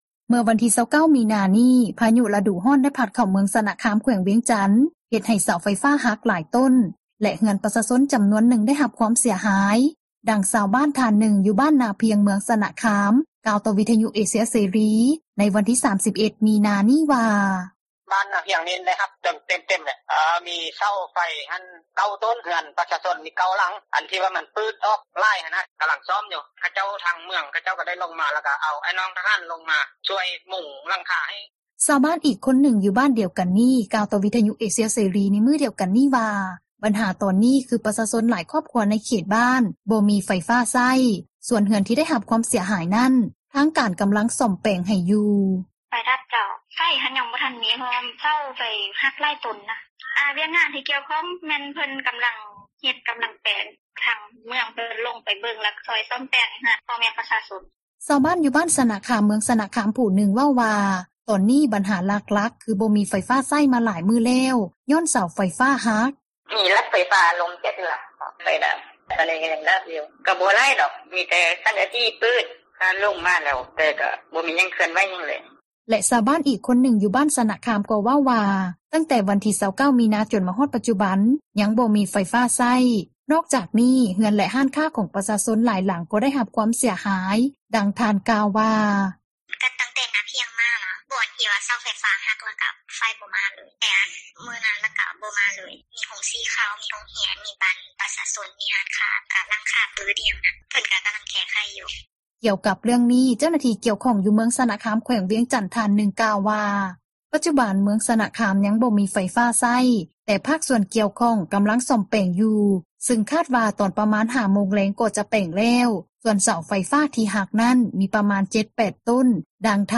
ນັກຂ່າວພົລເມືອງ